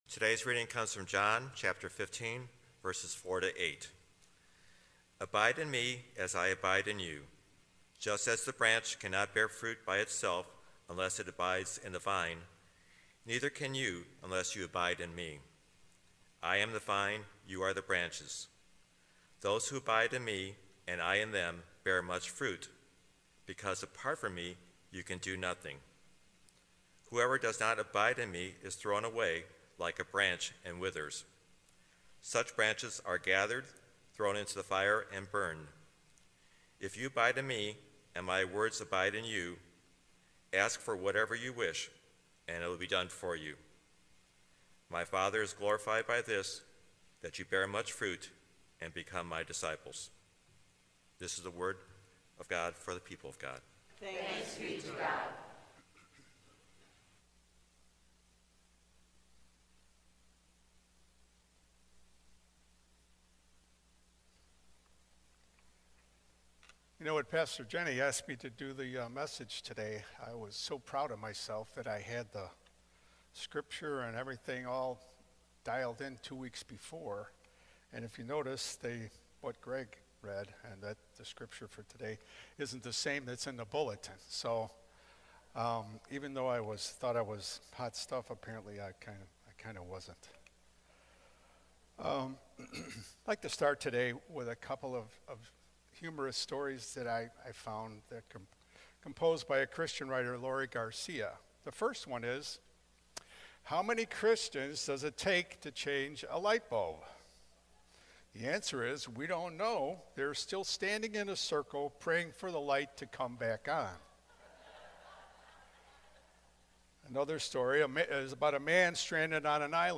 Sermons | Faith United Methodist Church of Orland Park